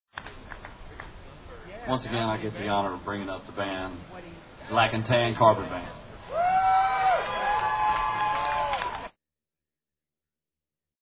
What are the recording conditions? Cincinnati's 2003 MMM was a 3 hour Rally held at the Downtown public gathering place, Fountain Square.